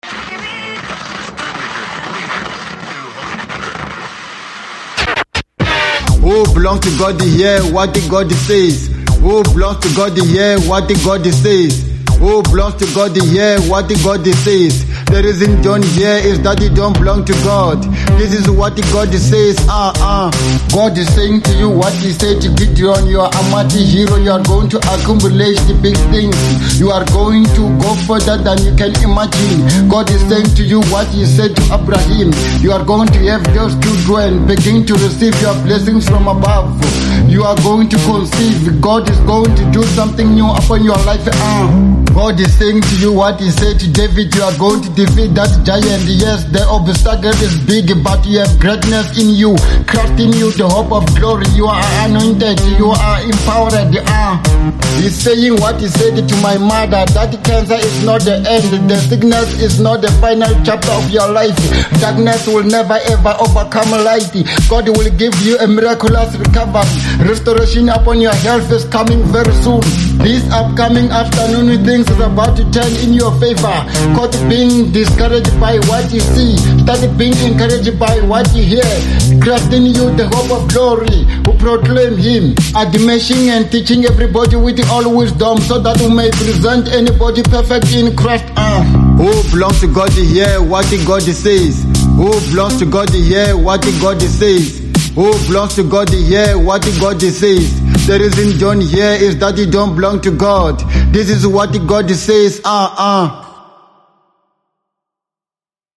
01:45 Genre : Hip Hop Size